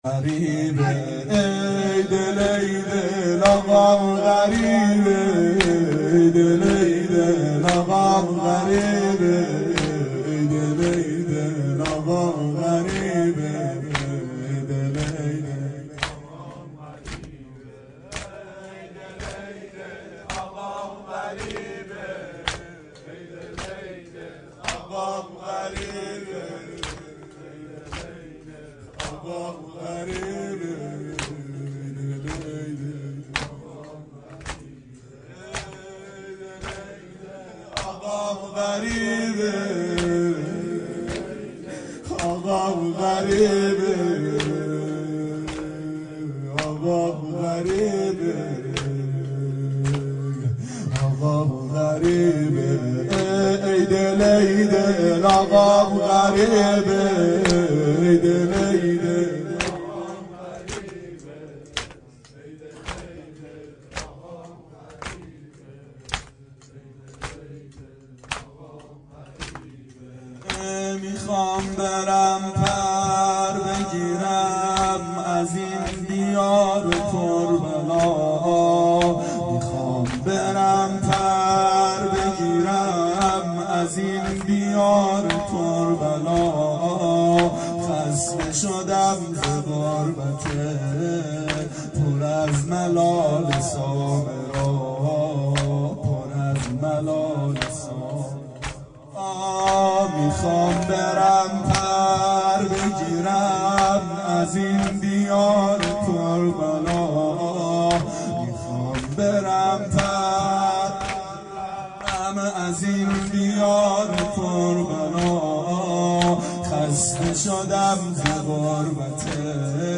• شب شهادت امام هادی علیه السلام 92 محفل شیفتگان حضرت رقیه سلام الله علیها